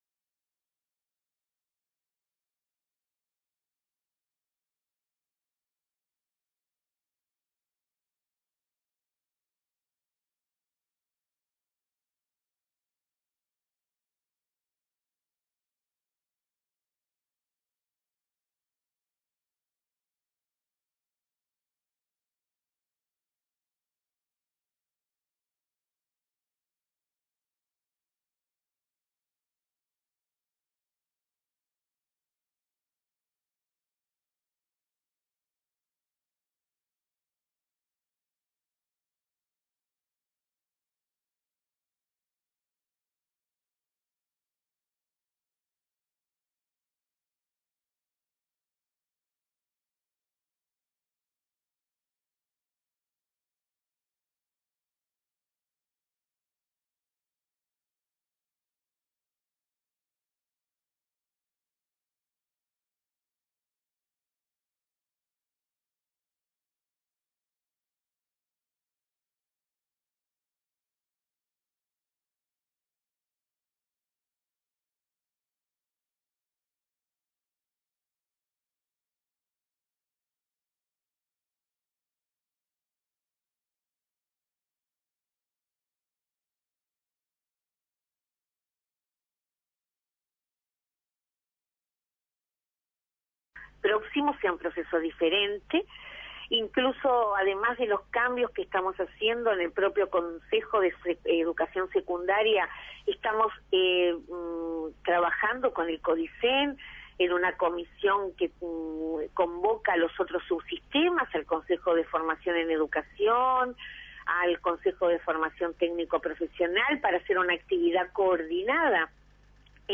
En diálogo con En Perspectiva, Celsa Puente detalló la situación actual en lo que se aplica al sistema de la elección de horas docentes y las distintas realidades que atraviesan los liceos de todo el país.